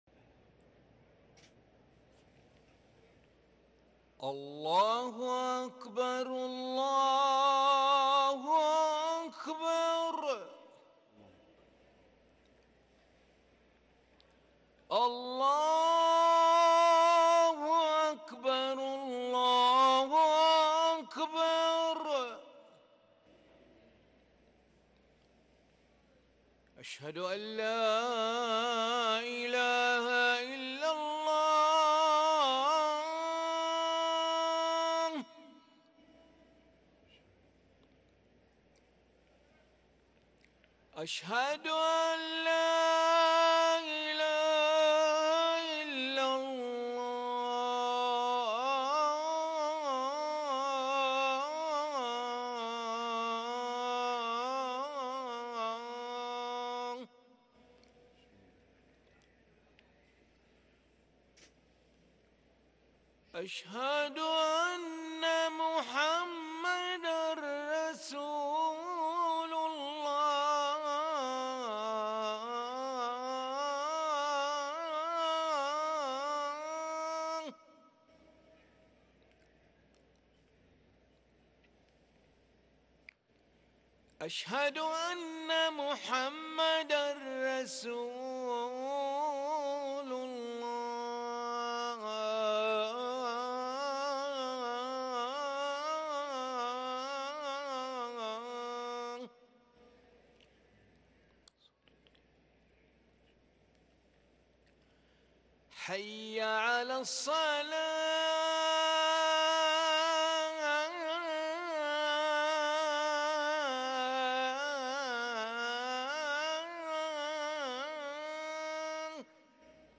أذان العشاء للمؤذن علي ملا الأحد 15 صفر 1444هـ > ١٤٤٤ 🕋 > ركن الأذان 🕋 > المزيد - تلاوات الحرمين